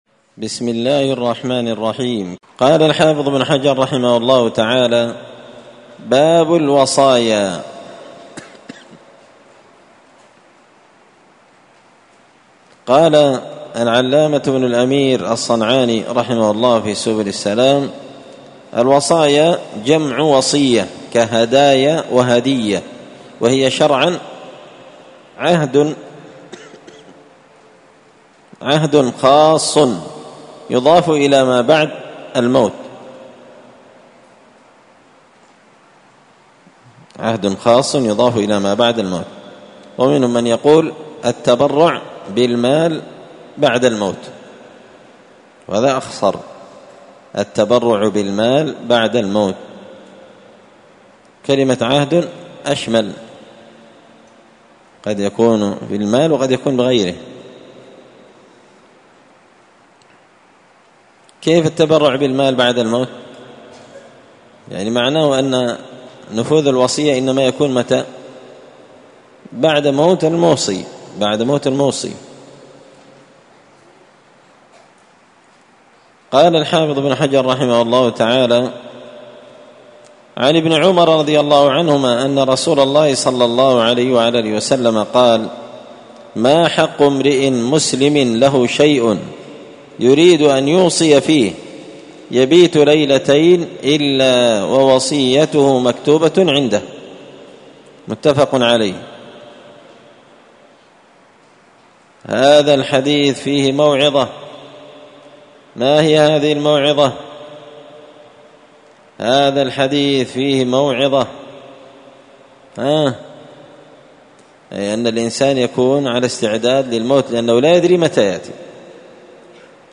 مسجد الفرقان_قشن_المهرة_اليمن